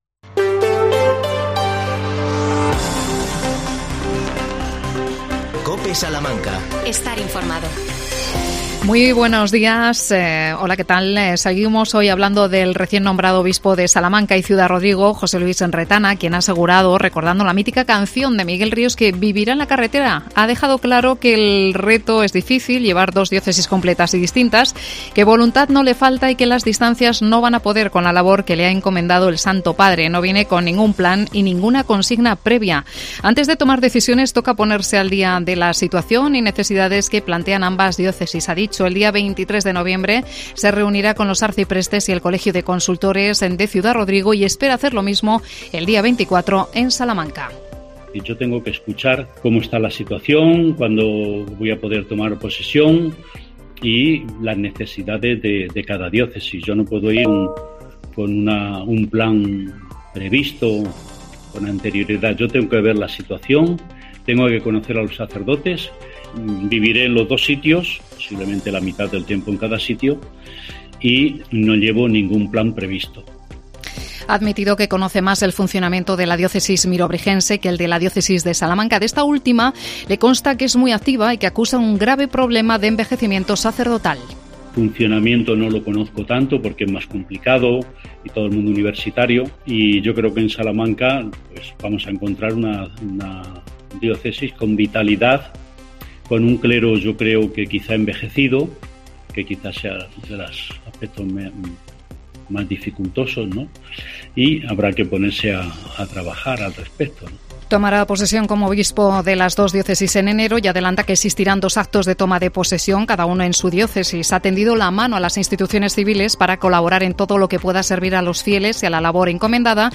AUDIO: Declaraciones del Obispo de Salamanca y Ciudad Rodrigo. Inauguración de GAChs. Polémica con la contratación municipal.